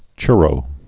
(chrō)